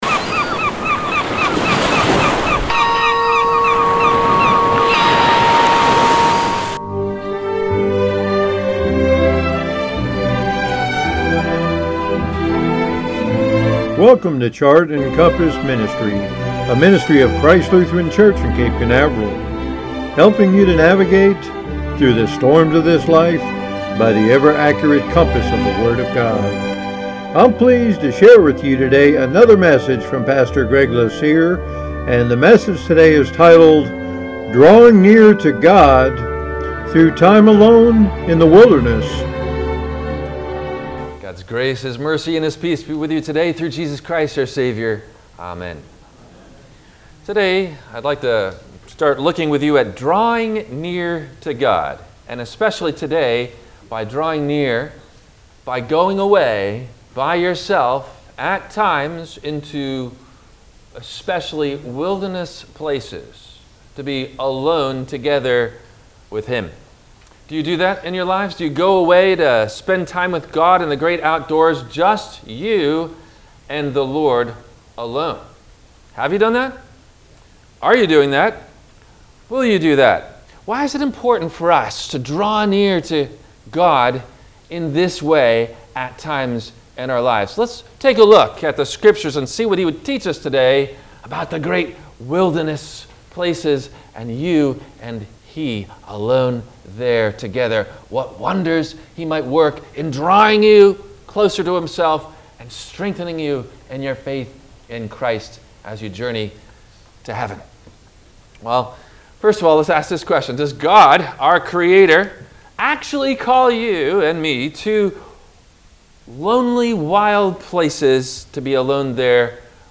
Drawing Near To God Thru Time Alone In The Wilderness – WMIE Radio Sermon – March 14 2022 - Christ Lutheran Cape Canaveral